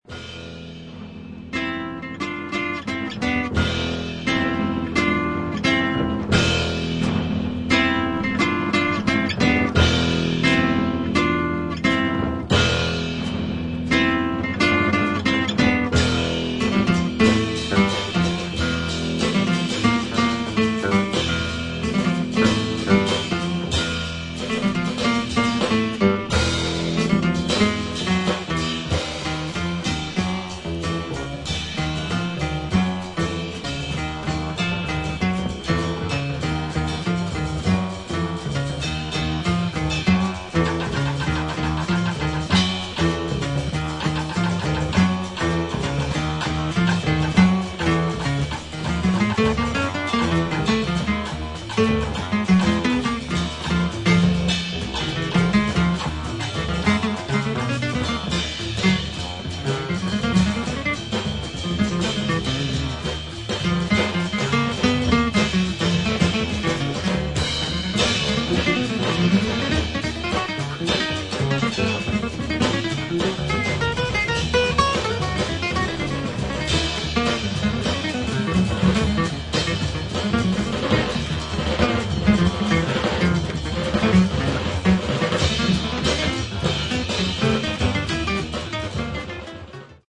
アメリカン・ルーツをベースにしながらも、中東的なフレーズが印象的な楽曲など、自由度の高いセッション・フリージャズ傑作。